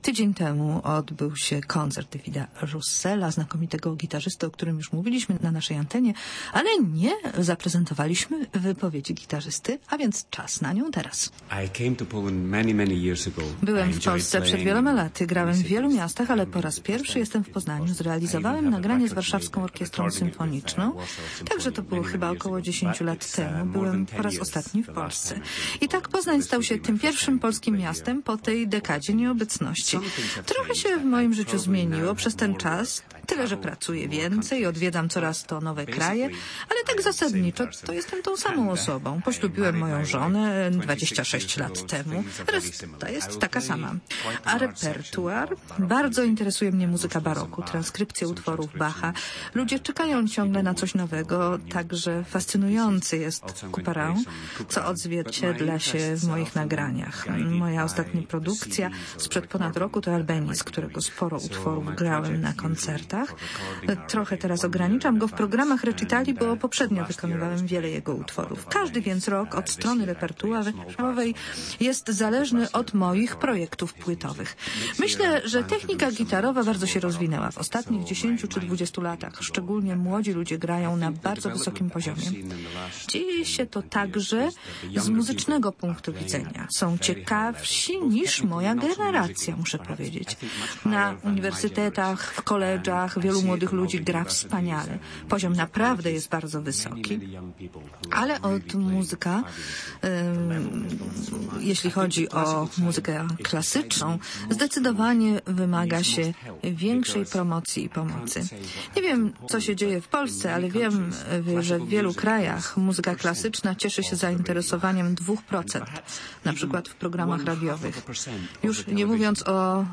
Akademia Gitary Polskiej - rozmowy